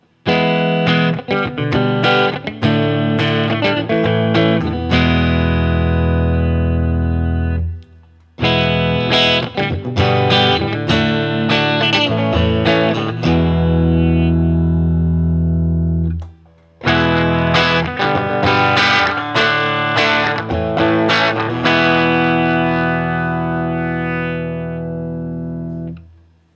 Open Chords #2
As usual, for these recordings I used my normal Axe-FX Ultra setup through the QSC K12 speaker recorded into my trusty Olympus LS-10.
For each recording I cycle through the neck pickup, both pickups, and finally the bridge pickup.
A guitar like this is really about that semi-hollow sound, and it delivers that in every position and on every fret, though that sort of hollow timbre can obviously get lost when the gain is up high and the effects are set to overwhelming.